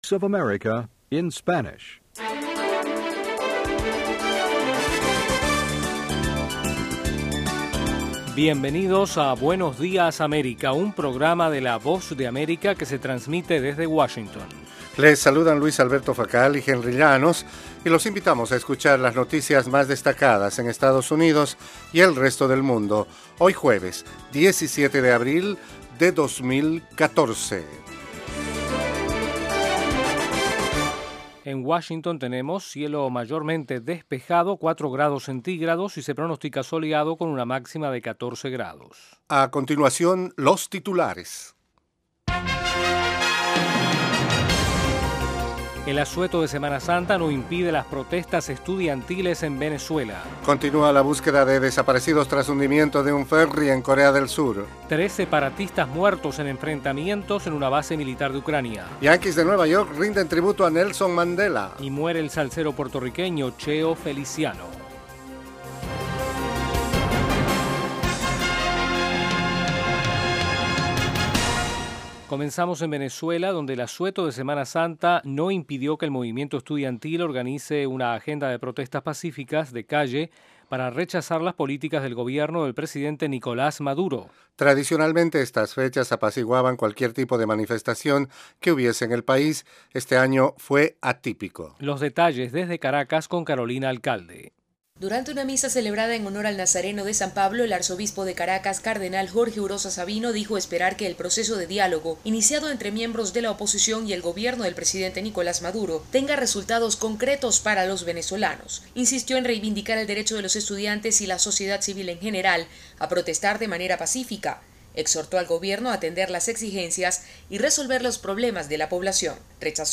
Buenos días América es un programa informativo diario de media hora dirigido a nuestra audiencia en América Latina. El programa se transmite de lunes a viernes de 8:30 a.m. a 9:00 a.m. [hora de Washington].